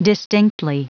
Prononciation du mot distinctly en anglais (fichier audio)
Prononciation du mot : distinctly